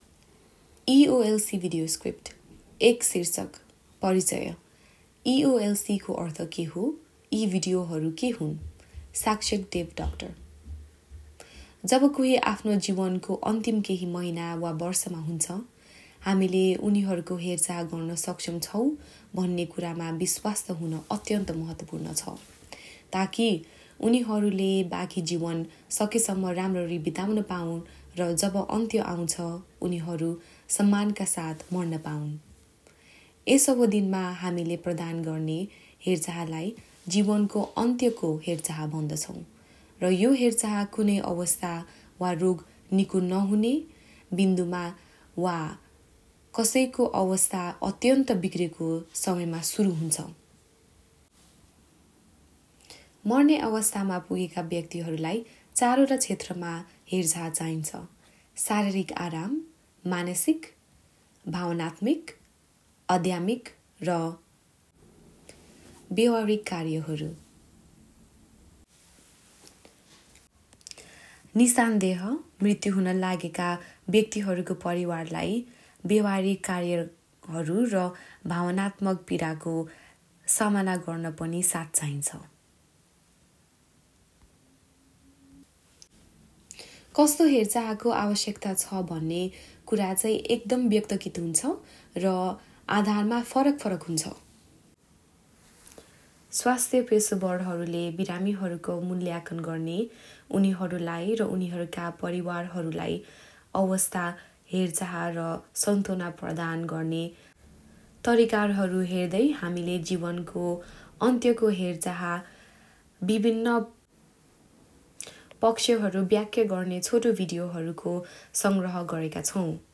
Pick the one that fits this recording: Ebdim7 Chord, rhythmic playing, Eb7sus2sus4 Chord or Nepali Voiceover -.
Nepali Voiceover -